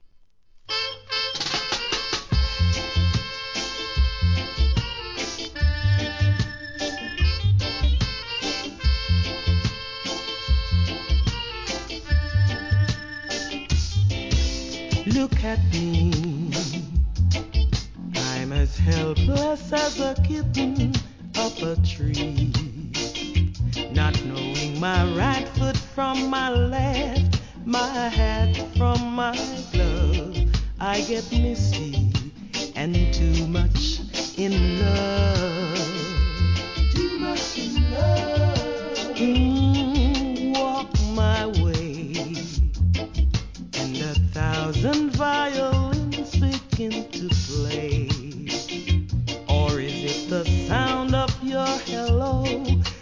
REGGAE
JAZZ古典好カヴァー!!!